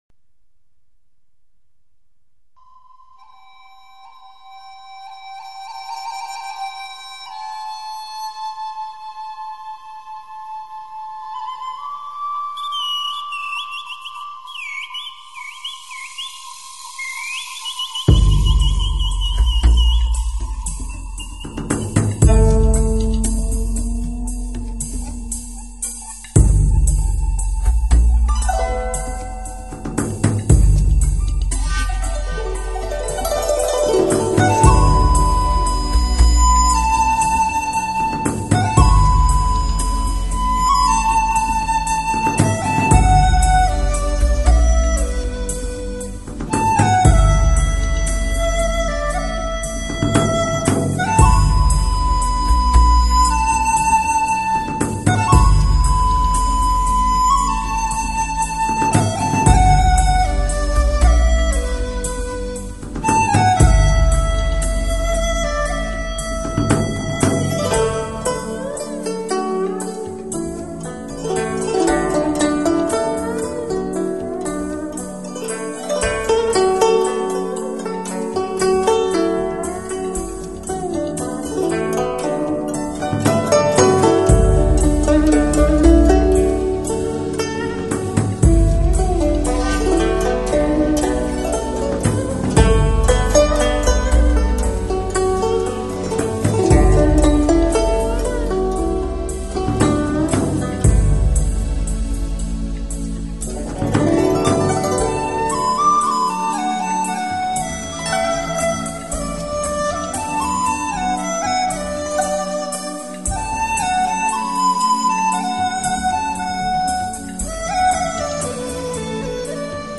纯音乐的世界，心事化作音符，纵情倾诉着守望已久的
很是震撼的音乐效果，收下，感谢提供